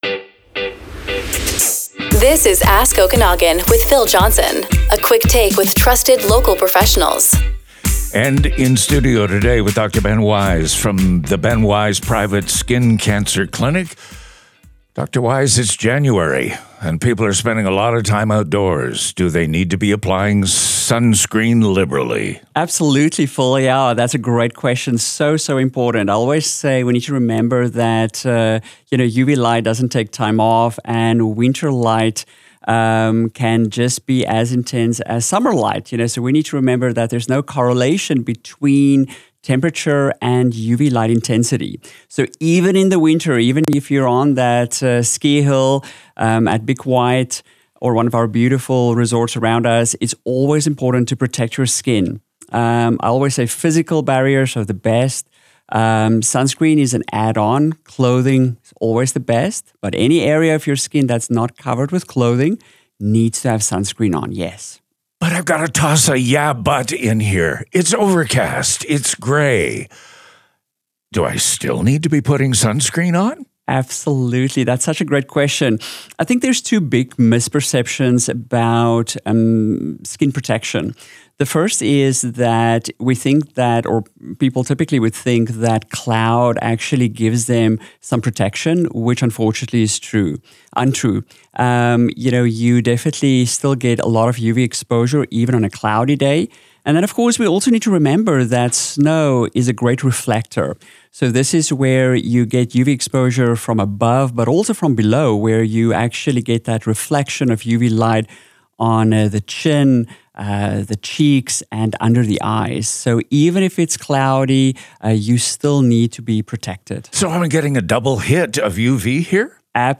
This media recording reflects a public interview and is shared for educational purposes only.